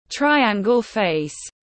Khuôn mặt góc cạnh tiếng anh gọi là triangle face, phiên âm tiếng anh đọc là /ˈtraɪ.æŋ.ɡəl feɪs/ .
Triangle face /ˈtraɪ.æŋ.ɡəl feɪs/
Triangle-face.mp3